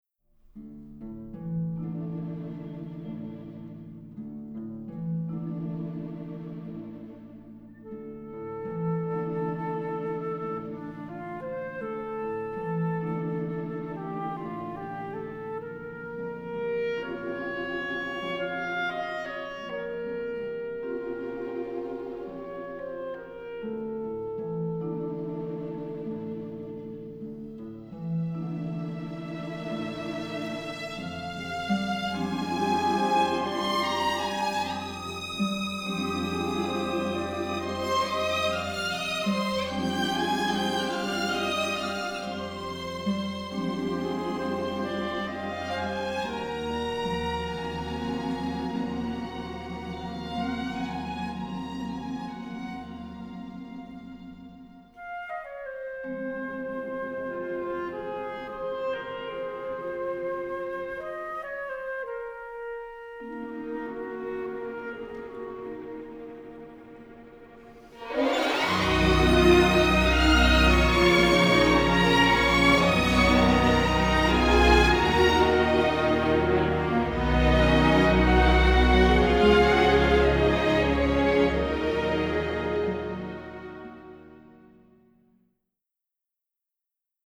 moving minor-key theme
crisp recording